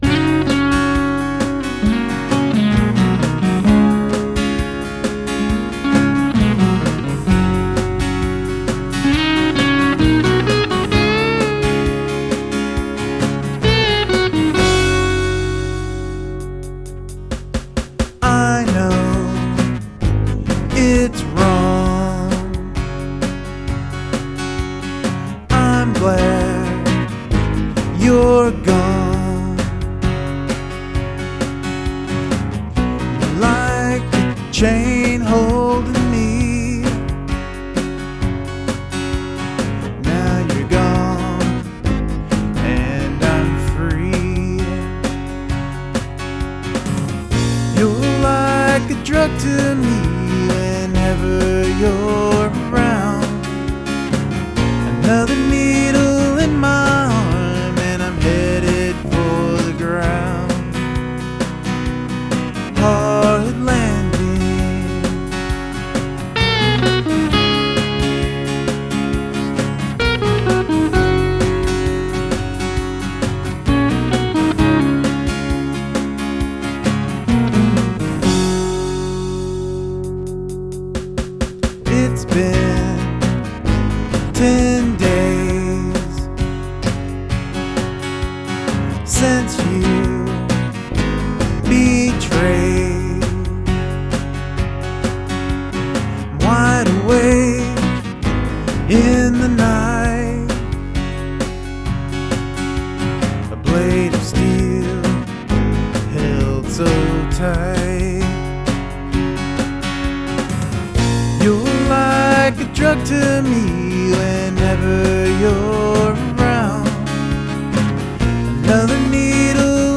It's missing real guitars and the singing is the first take except for the bridge.
Some brilliant and melodic chord changes, and your vocals sound great.
Love the phrasing on the Vocals!
Your voice sounds so good, smooth.  This is like some lovely old-school California Country-rock from days gone by, Gram Parsons kinda stuff, early POCO (before they became shit), angry but the anger is disguised by this lovely sunny california country-rock sound.